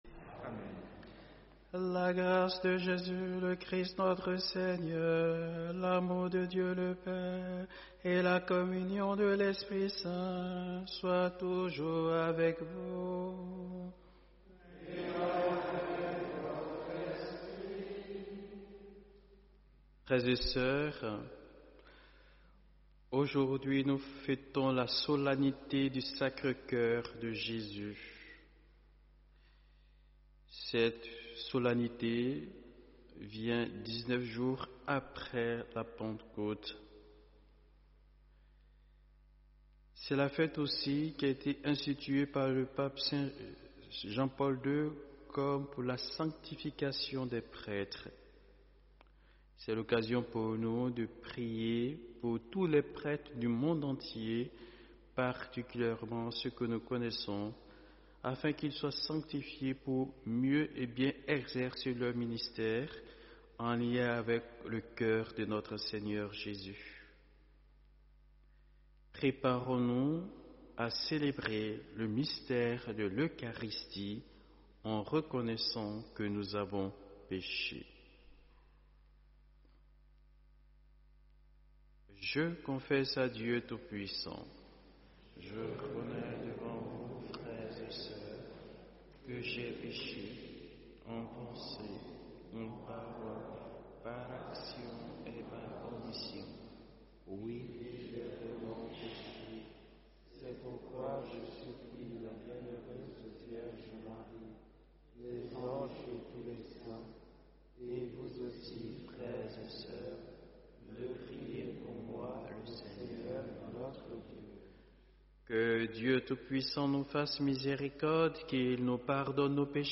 Vous pouvez retrouver l’intégralité de la MESSE sur Youtube